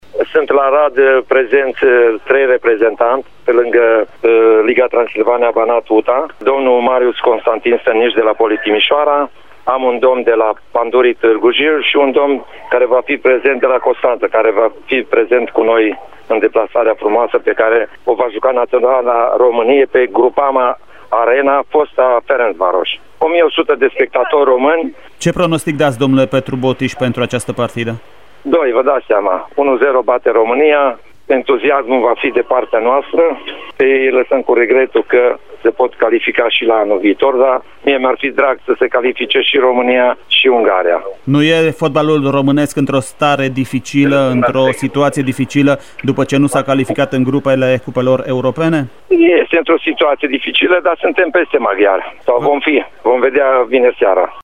a vorbit astăzi la postul nostru de radio despre această acţiune: